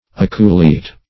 Aculeate \A*cu"le*ate\, a. [L. aculeatus, fr. aculeus, dim. of